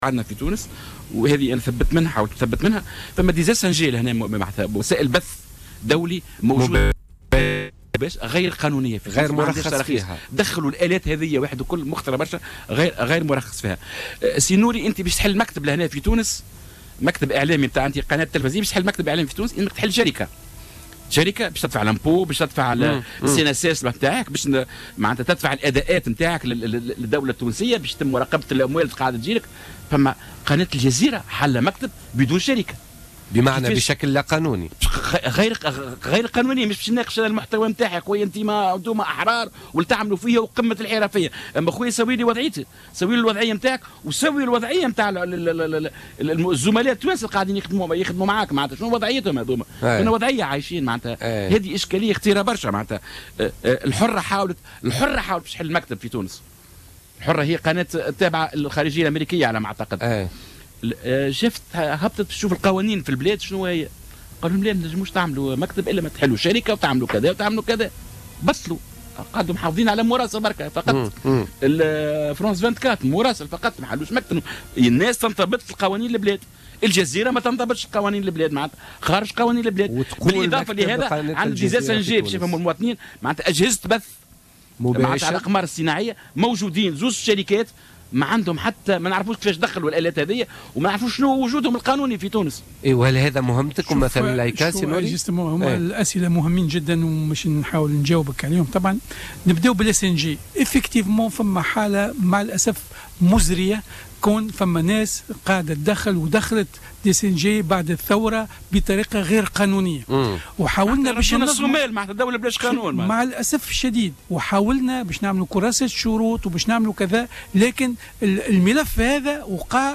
أكد النوري اللجمي رئيس الهيئة العليا المستقلة للاتصال السمعي البصري في تصريح للجوهرة أف أم في برنامج بوليتكا لليوم الخميس 10 مارس 2016 أن هناك قنوات أجنبية تبث مباشرة من تونس وعبر الأقمار الاصطناعية بصفة غير قانونية.